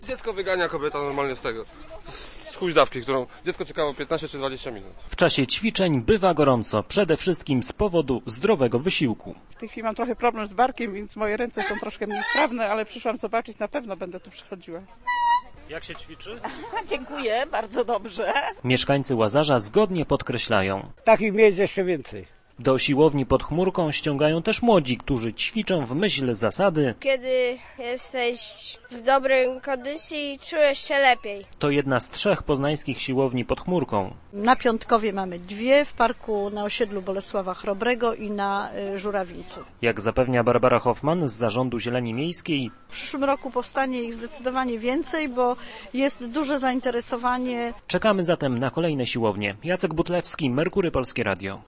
u1bzykdsr3583sj_silownia_pod_chmurka_relacja.mp3